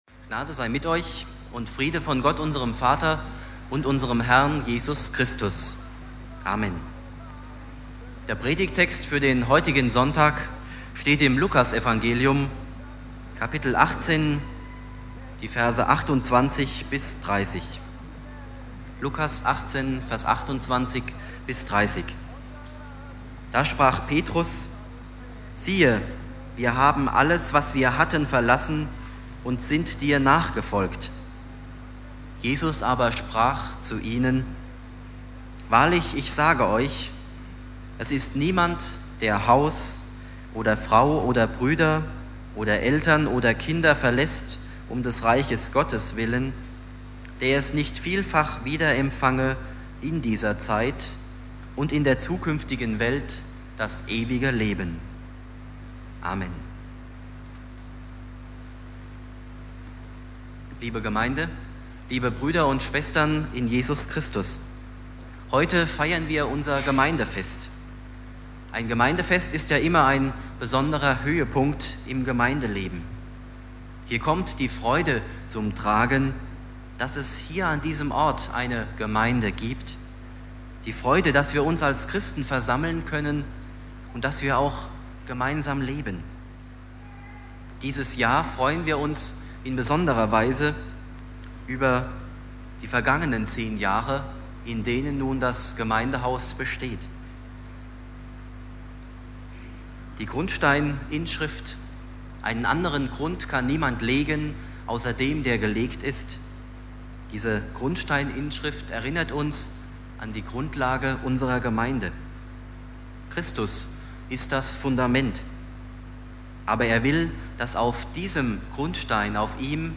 Predigt
Wie werden wir lebendige Bausteine in der Gemeinde? (Gemeindefestgottesdienst - zehn Jahre Gemeindehaus) Inhalt der Predigt: Aktives Christsein heißt: 1) Loslassen 2) Jesus folgen 3) Beschenkt werden Bibeltext: Lukas 18,28-30 Dauer: 22:15 Abspielen: Ihr Browser unterstützt das Audio-Element nicht.